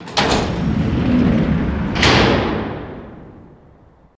Door, Metallic, Slide Shut